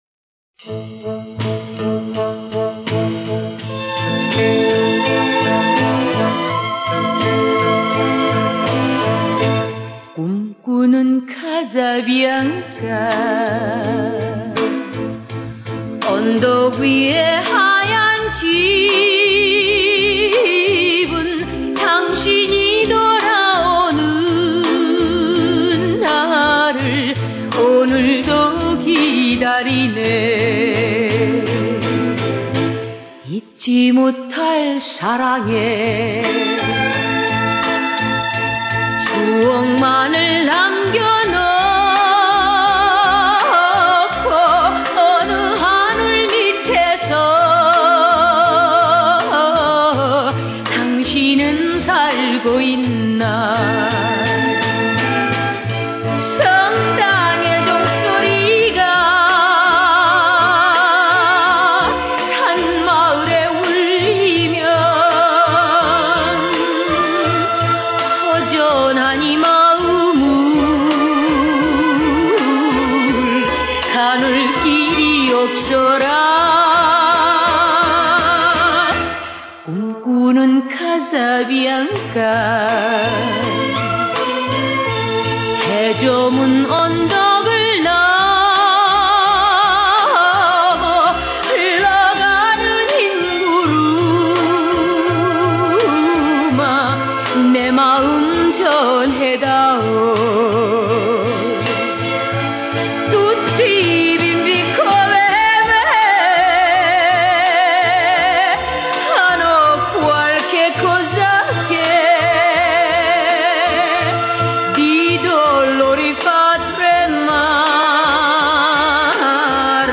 번안곡